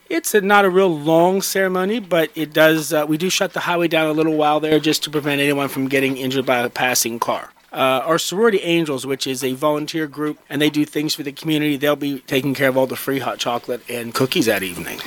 Mayor Jack Coburn tells WCBC the event officially kicks off the Lonaconing Christmas season that includes a Coney Christmas on December 14th…